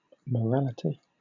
Ääntäminen
Southern England UK : IPA : /məˈɹælɪti/